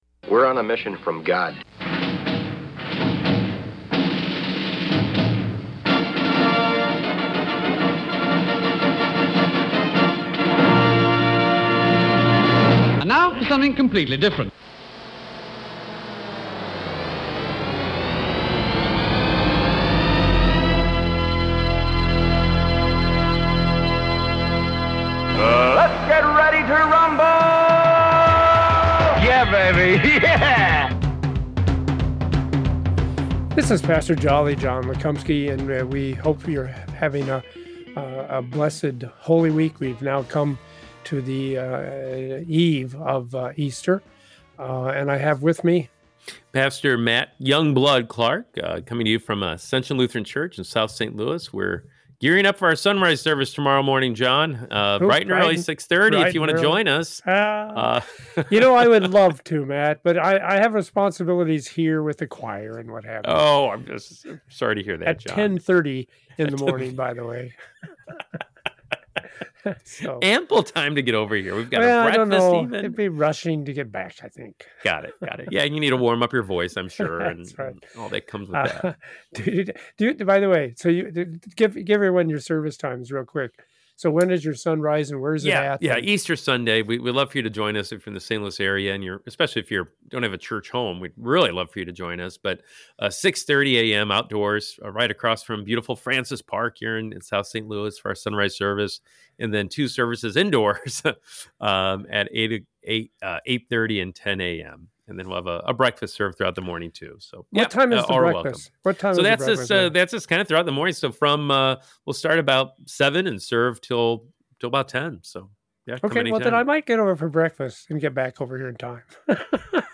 a humorous approach to Bible Study